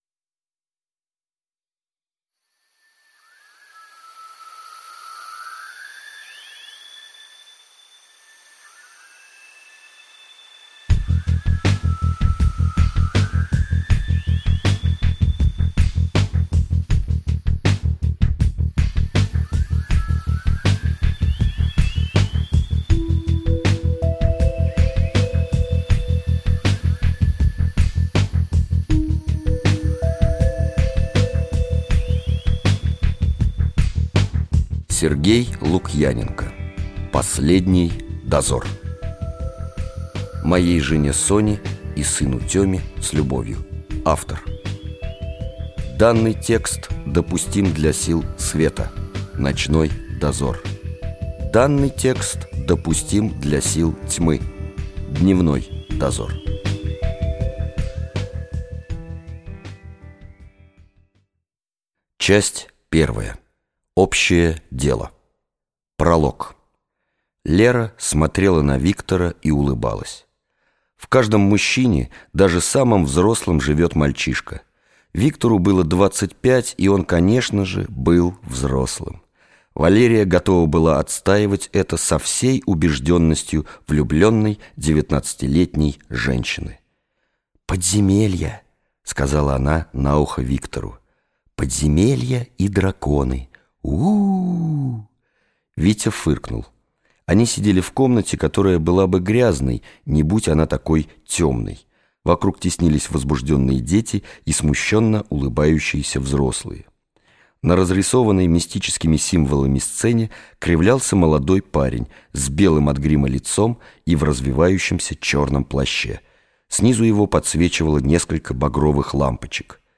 Аудиокнига Последний Дозор - купить, скачать и слушать онлайн | КнигоПоиск